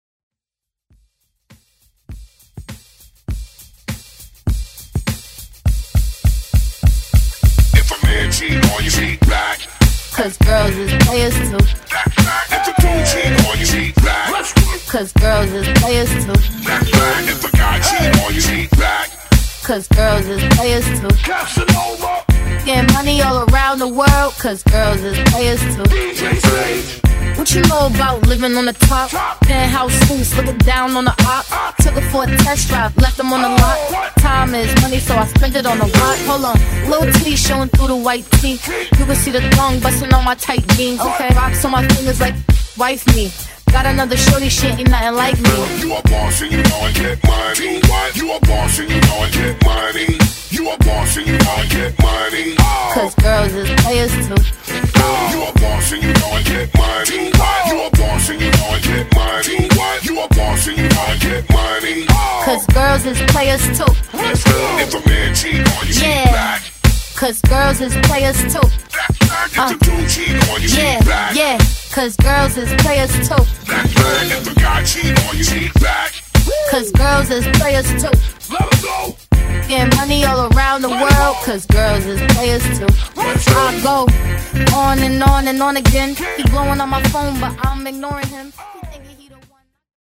Genres: BOOTLEG , EDM , TOP40
Clean BPM: 128 Time